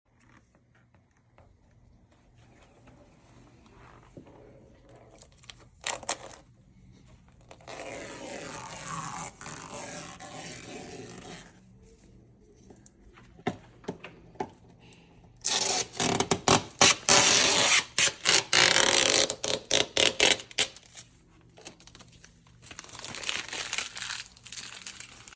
Knifeless Tape On A Mercedes sound effects free download